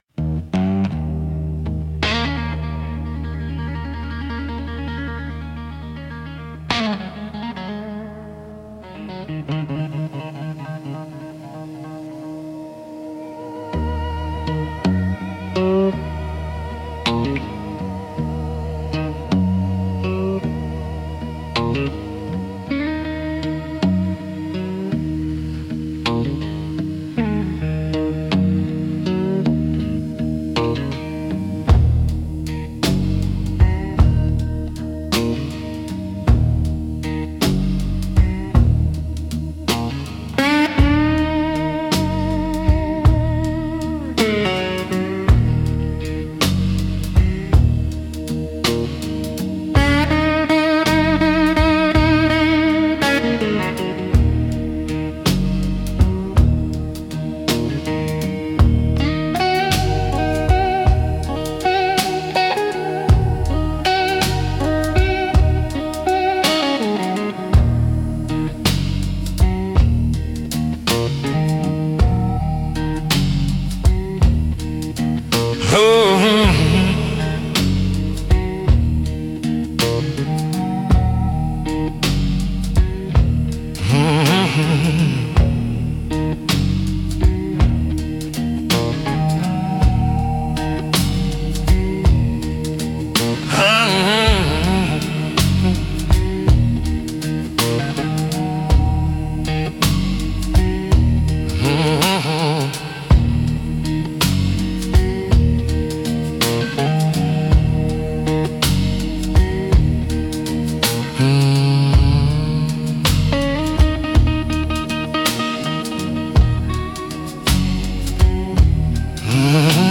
Instrumental - The Stillness Before the Bend